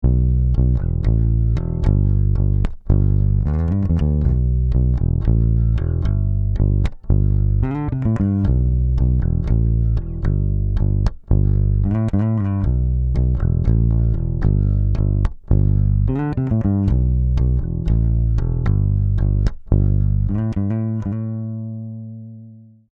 6 db RMS Gain Reduction, 10 db peak
Captured with a Motu 192
Bass - Stock DBX 160x